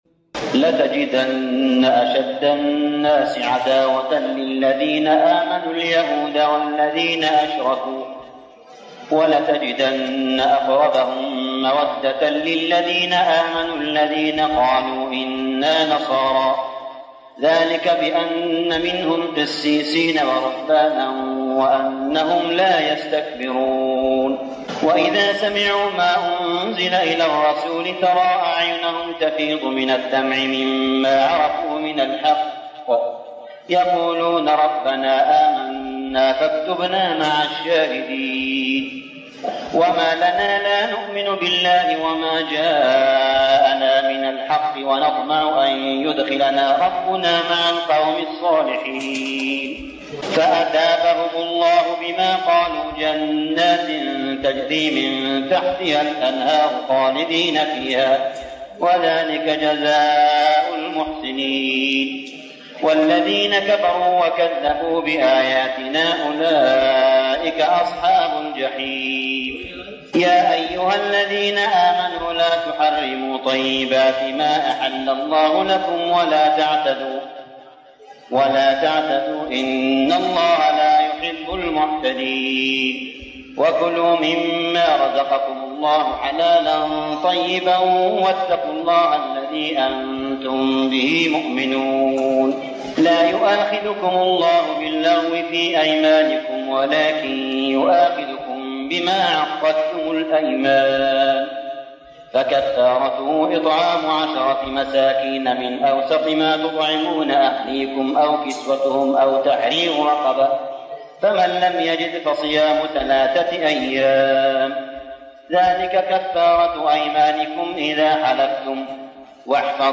صلاة التراويح ليلة 7-9-1411هـ سورتي المائدة 82-120 و الأنعام 1-35 | Tarawih prayer Surah Al-Ma'idah and Al-An'am > تراويح الحرم المكي عام 1411 🕋 > التراويح - تلاوات الحرمين